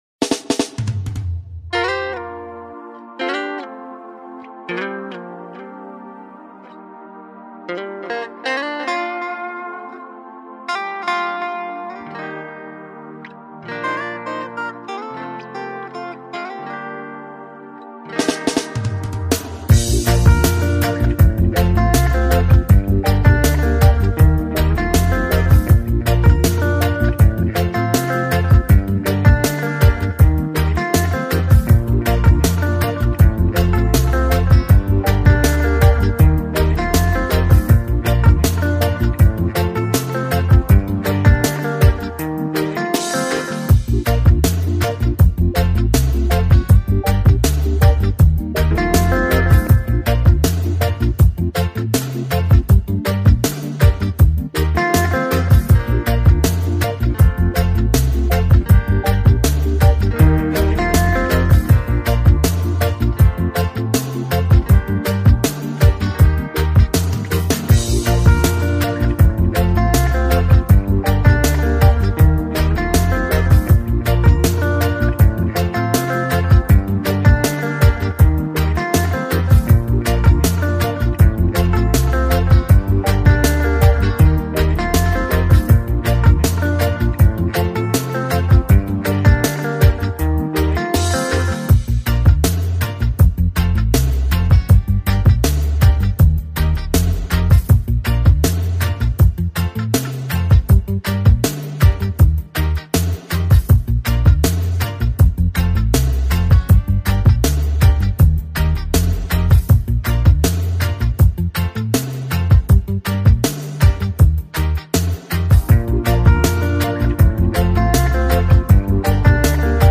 KGecp7lvtPQ_reggae-instrumental-NegMyvzx.mp3